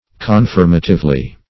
-- Con*firm"a*tive*ly , adv.